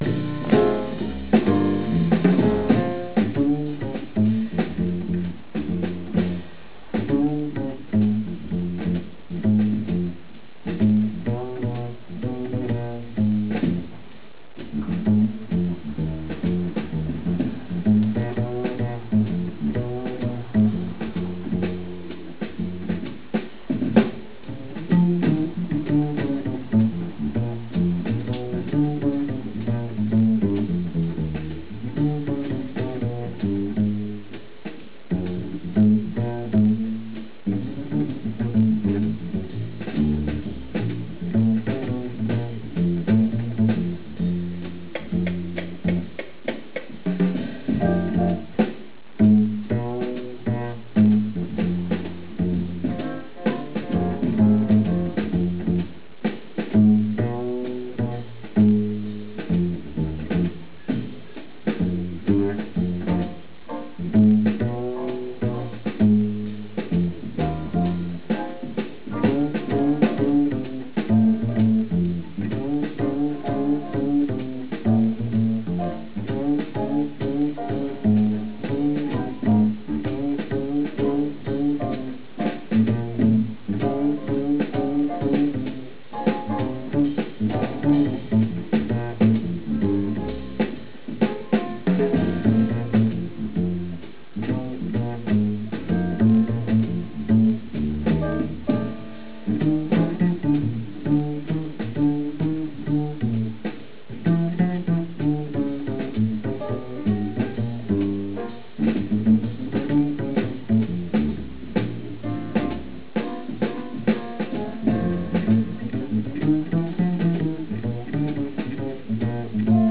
Le chorus